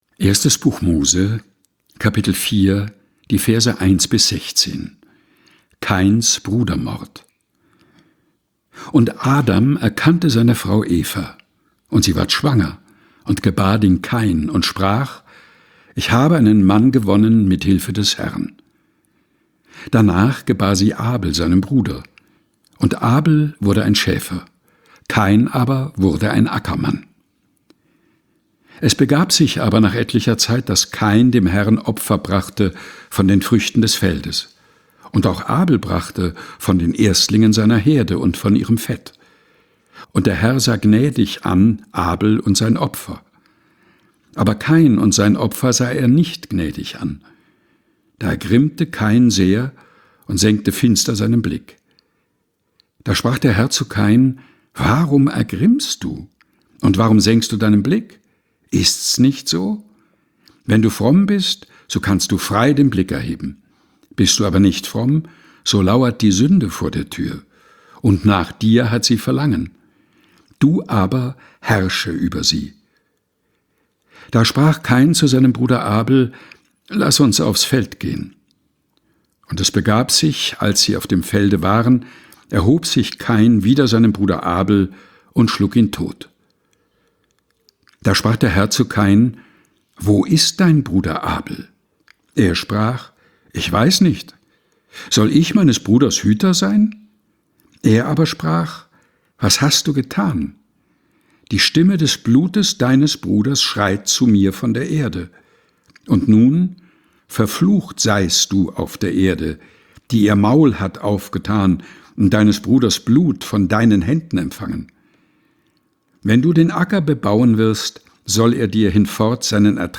Ohrenweide ist der tägliche Podcast mit Geschichten, Gebeten und Gedichten zum Mutmachen und Nachdenken - ausgesucht und im Dachkammerstudio vorgelesen von